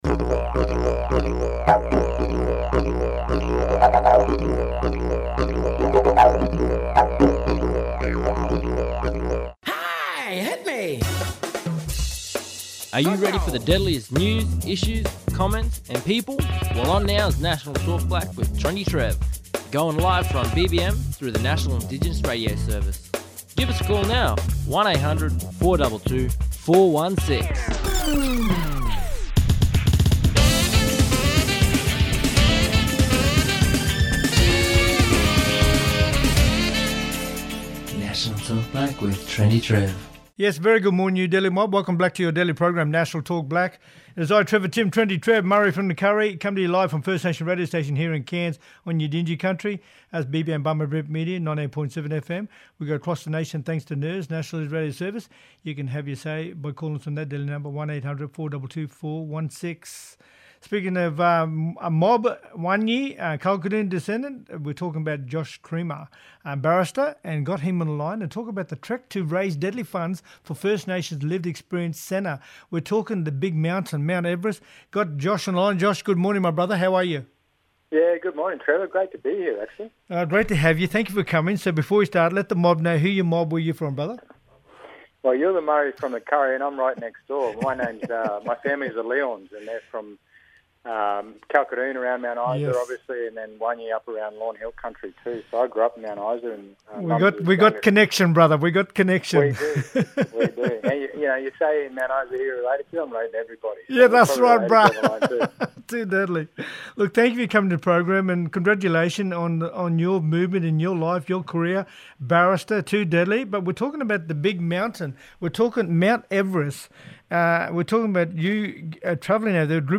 On todays National Talkblack via NIRS – National Indigenous Radio Service we have: